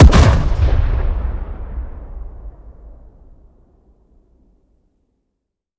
weap_rpapa7_fire_plr_atmo_01.ogg